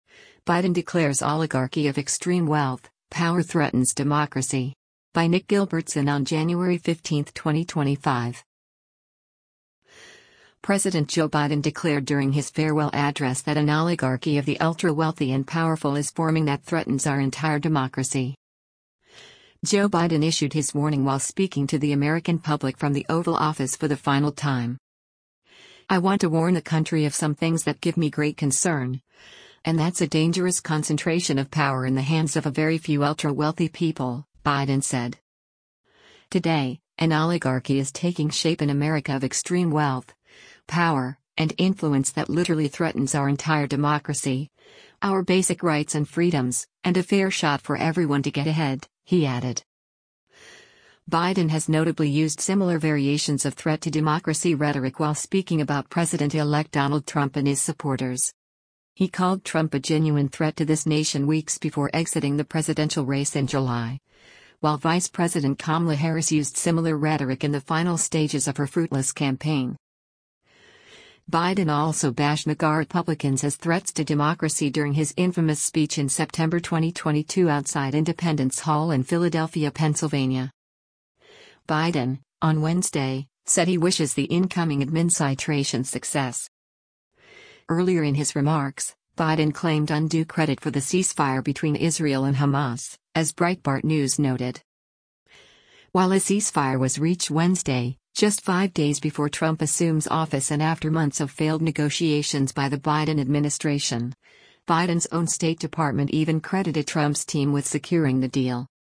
President Joe Biden declared during his farewell address that an “oligarchy” of the “ultra-wealthy” and powerful is forming that “threatens our entire democracy.”
Joe Biden issued his warning while speaking to the American public from the Oval Office for the final time.